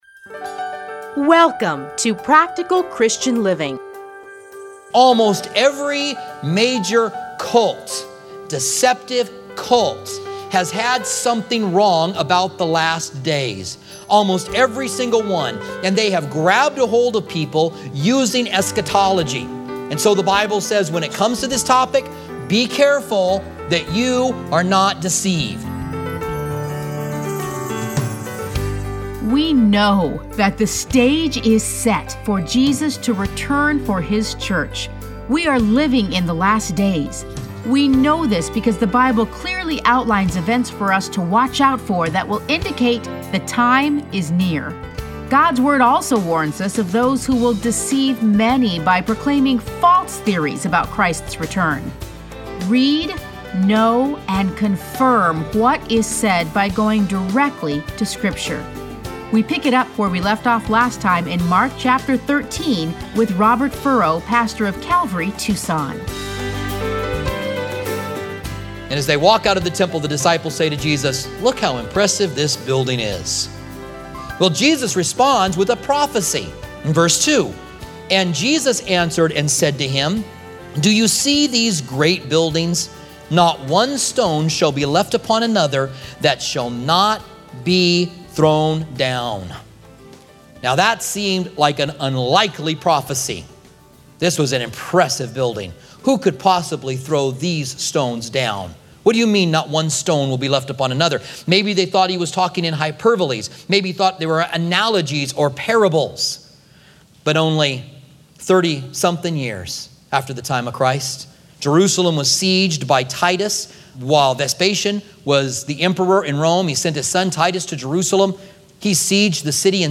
Listen to a teaching from Mark 13.